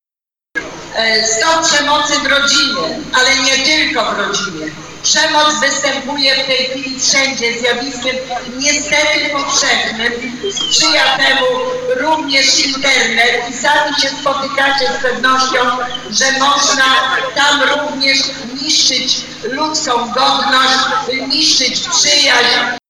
Kilka słów podczas happeningu powiedziała także Józefa Błajet - Przewodnicząca Rady Powiatu w Żninie